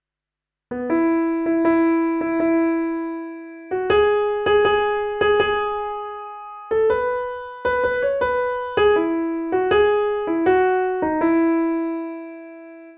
Here’s the melody stripped of all accompaniment to make things a little easier:
The Noble Duke of York: Unaccompanied Melody